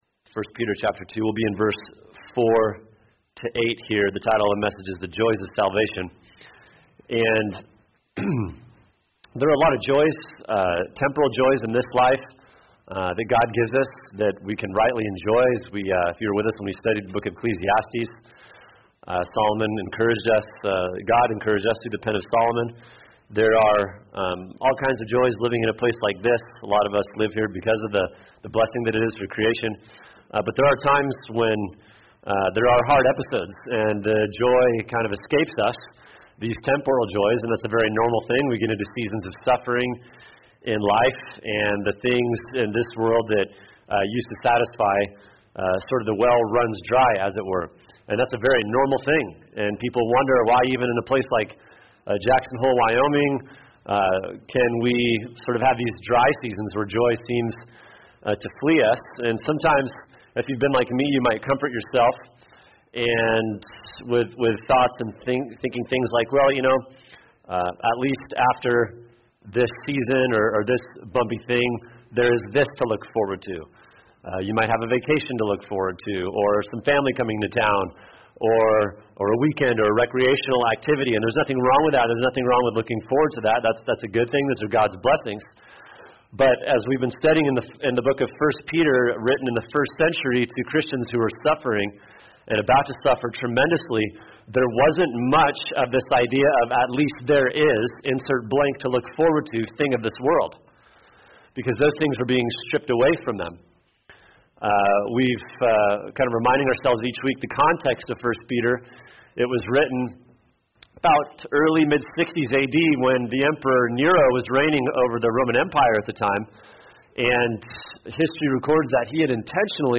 [sermon] 1 Peter 2: 4-8 The Joys of Salvation | Cornerstone Church - Jackson Hole